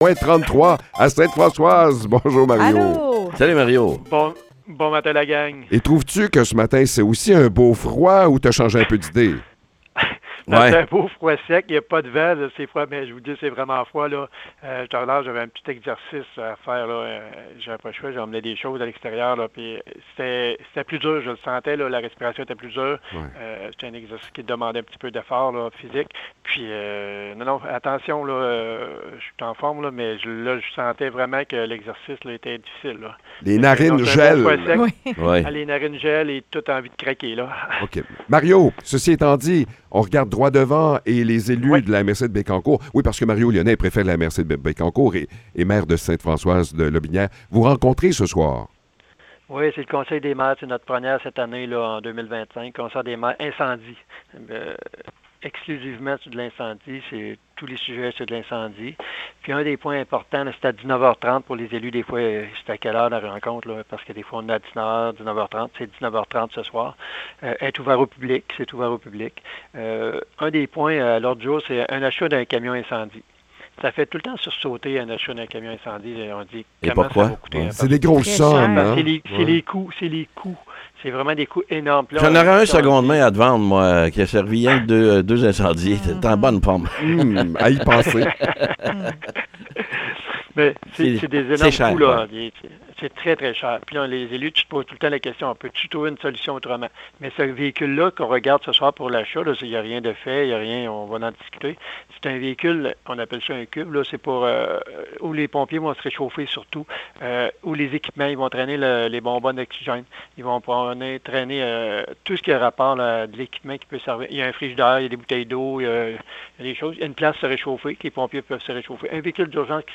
Échange avec Mario Lyonnais
Mario Lyonnais, maire de Ste-Françoise et préfet de la MRC de Bécancour, nous parle du sujet principal du conseil de ce soir : le domaine incendie.